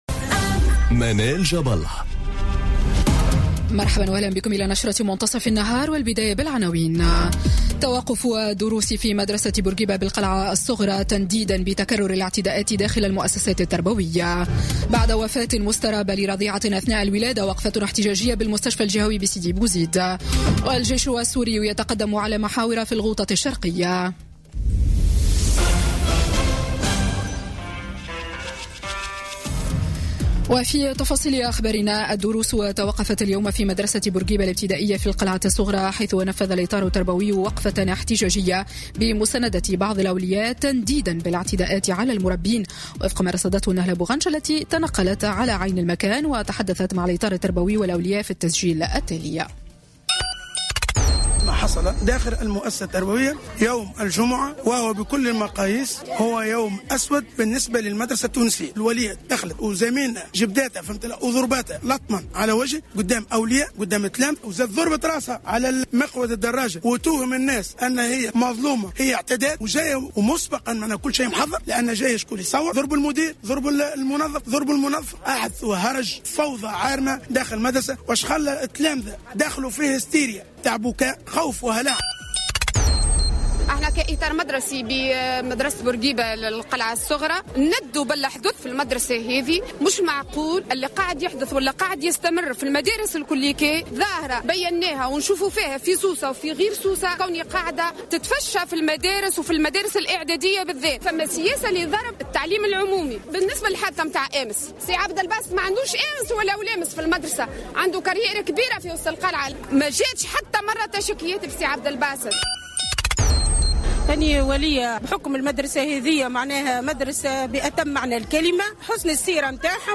نشرة أخبار منتصف النهار ليوم السبت 10 مارس 2018